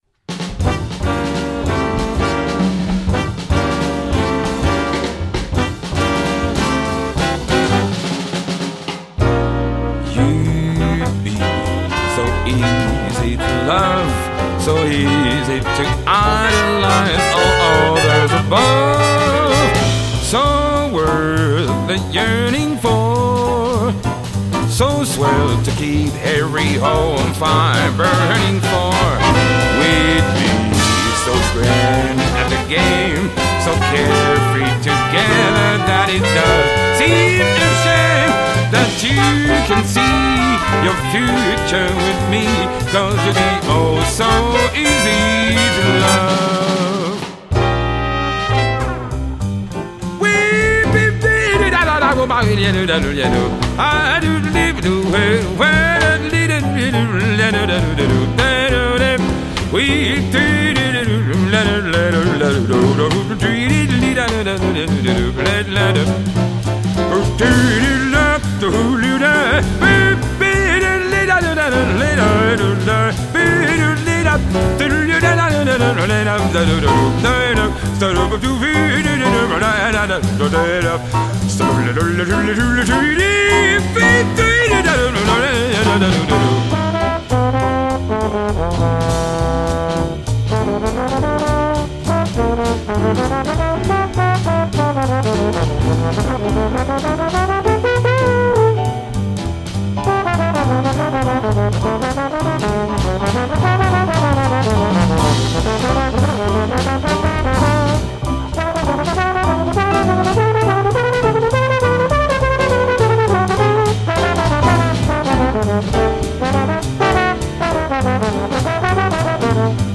dans le style "cool" des années cinquante
trompette
saxophone ténor
trombone
piano
contrebasse
batterie
chant